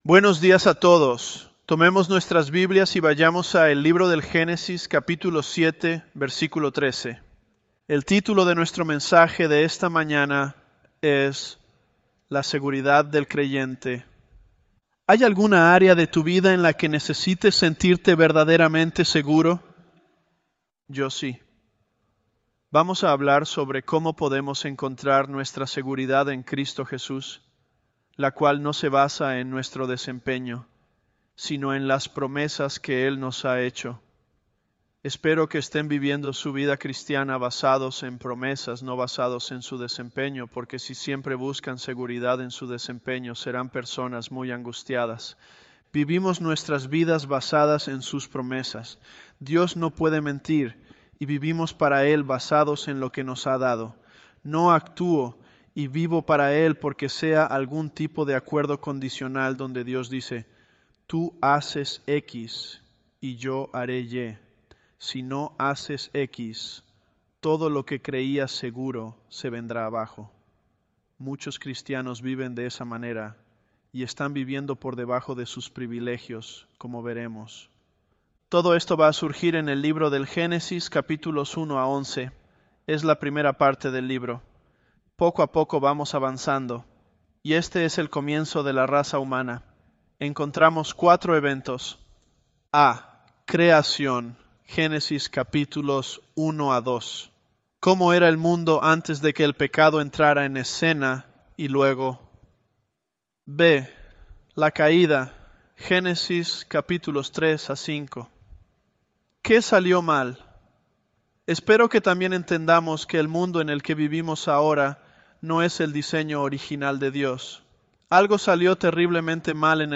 ElevenLabs_Genesis-Spanish031.mp3